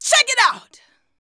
CHECKITOUT.wav